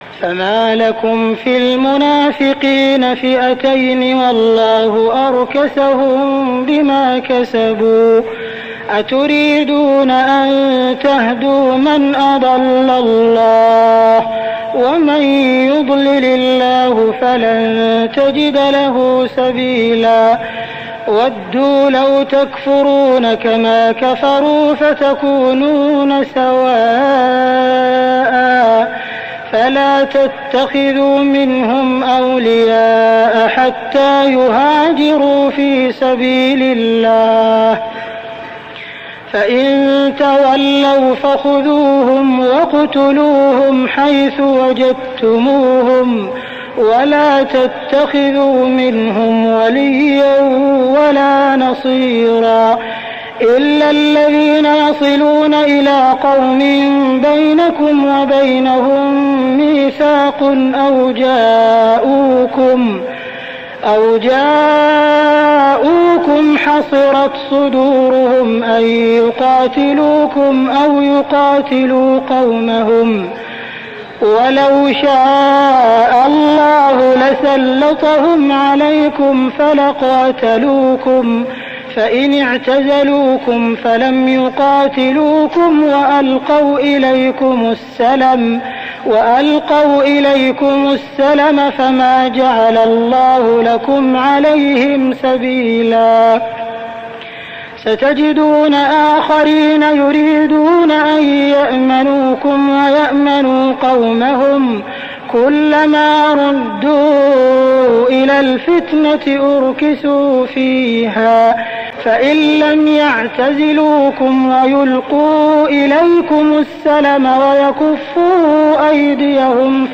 صلاة التراويح ليلة 5-9-1409هـ سورة النساء 88-147 | Tarawih prayer Surah An-Nisa > تراويح الحرم المكي عام 1409 🕋 > التراويح - تلاوات الحرمين